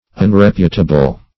Unreputable \Un*rep"u*ta*ble\, a.
unreputable.mp3